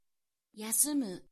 Prononciation-de-yasumu.mp3